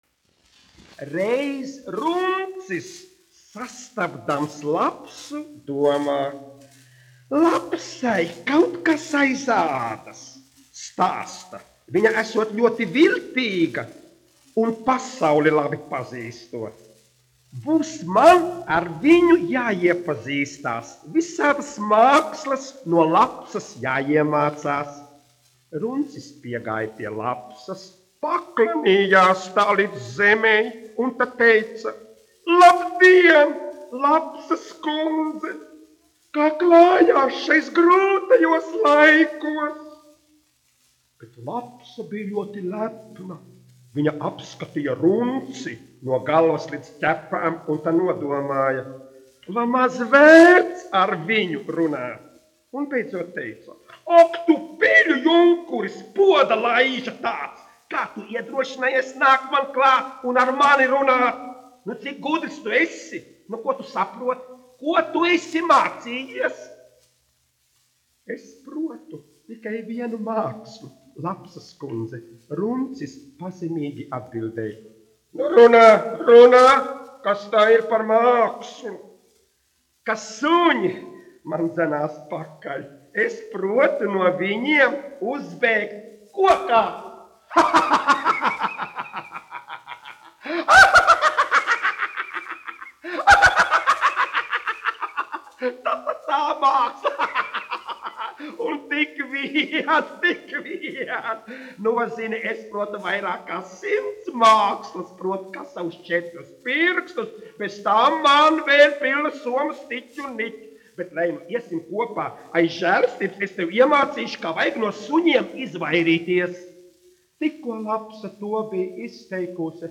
1 skpl. : analogs, 78 apgr/min, mono ; 25 cm
Tautas pasakas
Skaņuplate